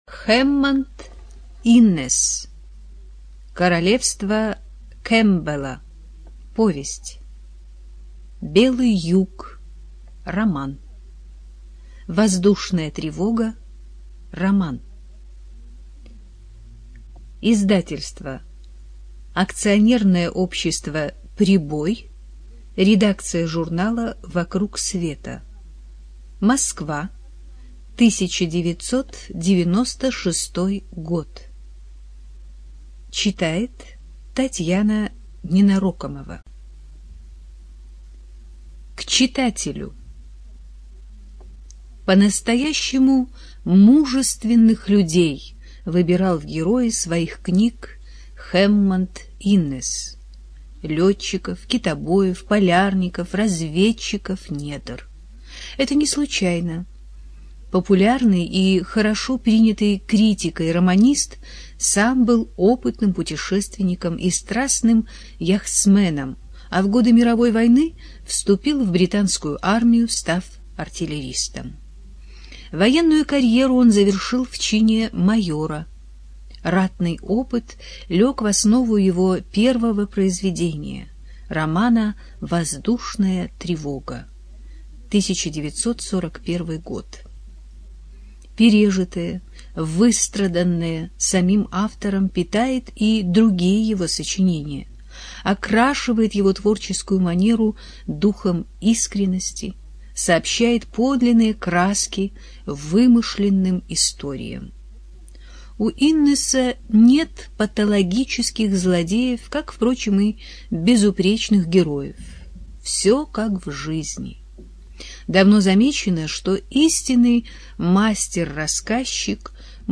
Студия звукозаписизвукотэкс